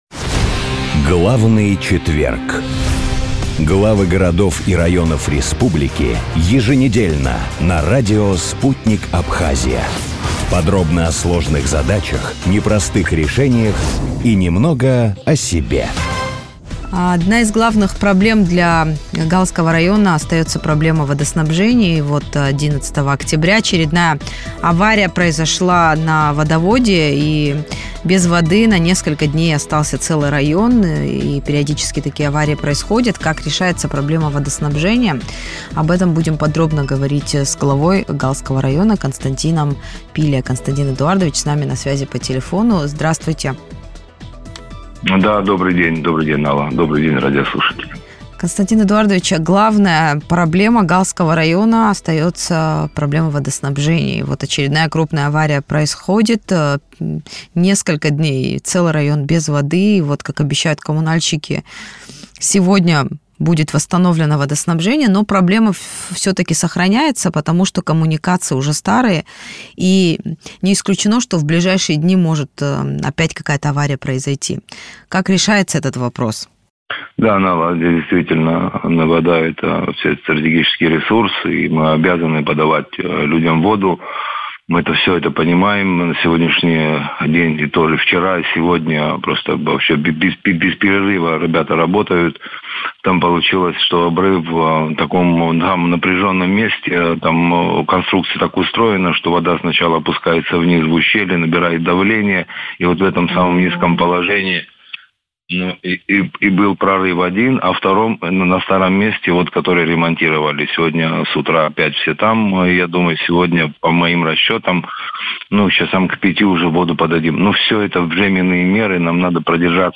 Водоснабжение, участие в Инвестпрограмме, День города: интервью с главой района